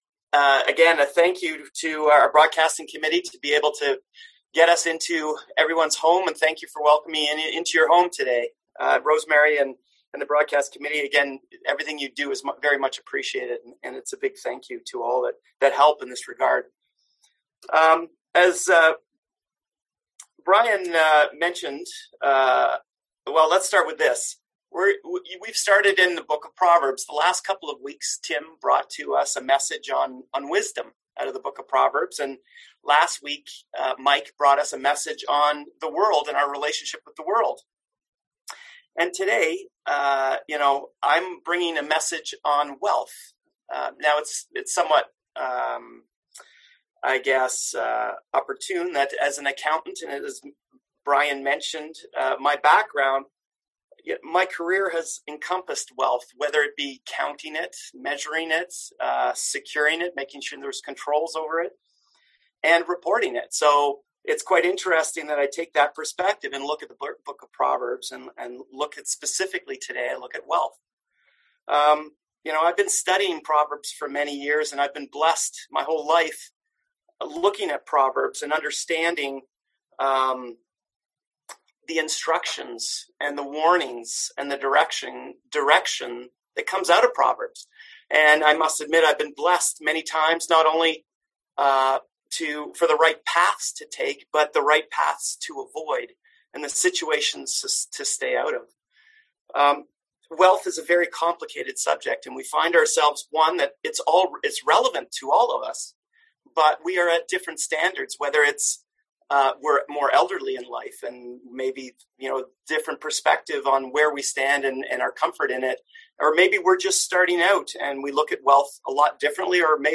Passage: Proverbs 23:4-5, Proverbs 11:28 Service Type: Sunday AM